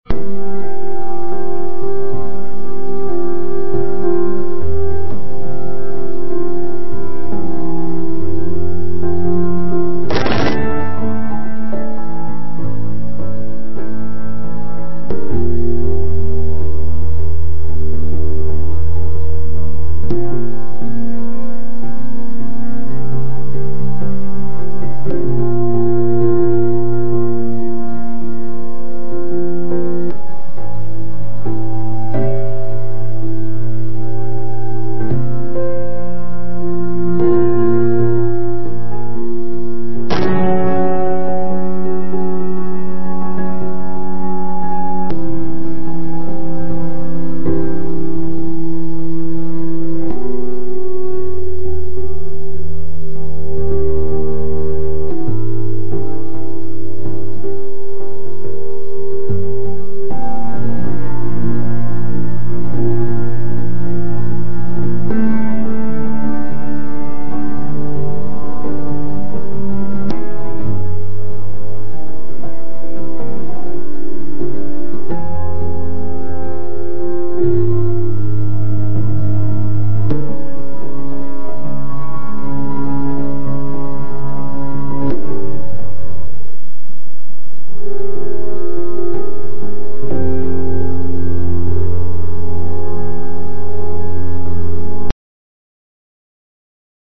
Unconditional piano music synthesis using SampleRNN.mp3